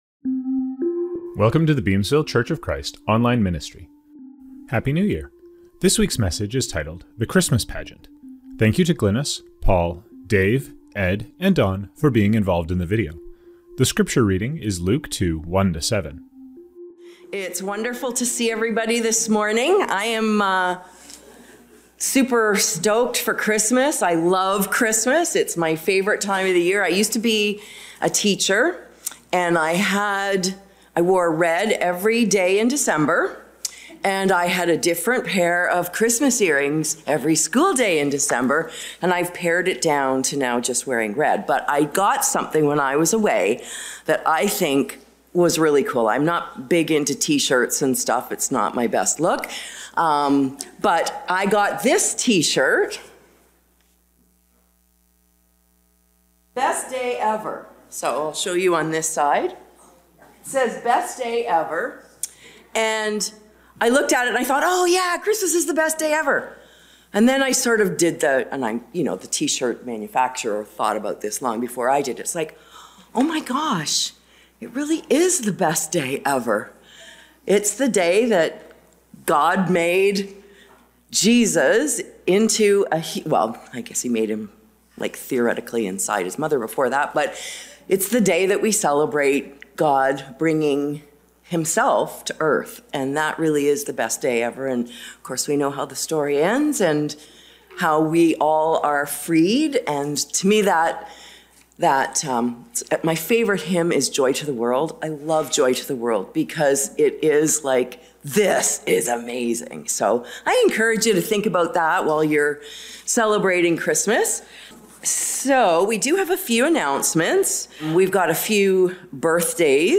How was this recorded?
Scriptures from this service: Communion - Romans 6:4, Ephesians 1:19-20.